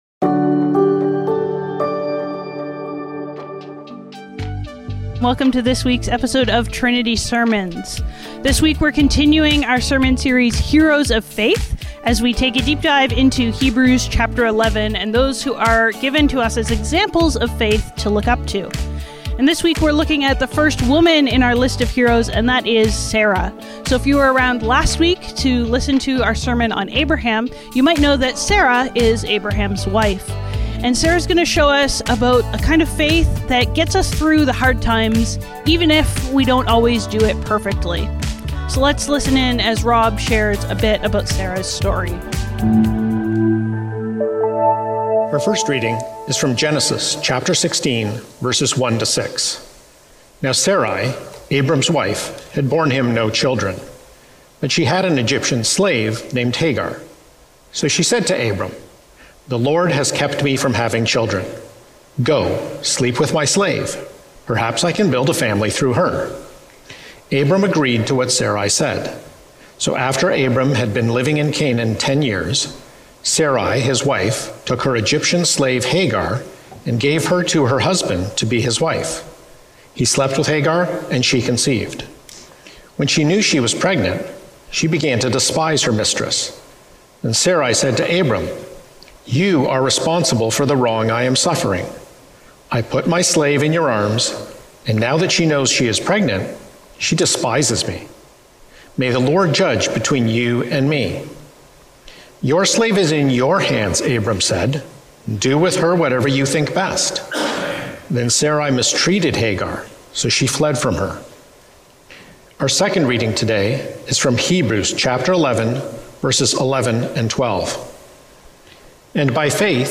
Trinity Streetsville - Sarah - Faith that Falters | Heroes of Faith | Trinity Sermons - Archive FM